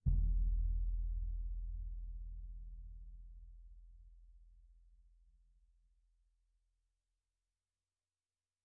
Orchestral Bass
bassdrum_hit_pp1.wav